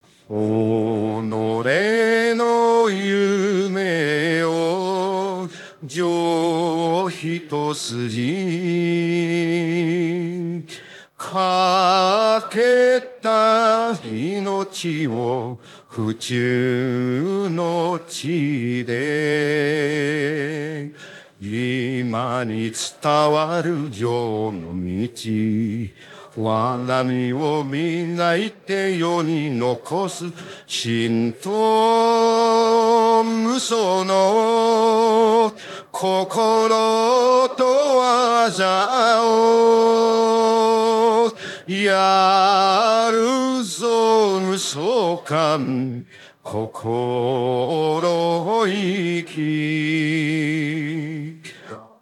夢想館の心意気」by AI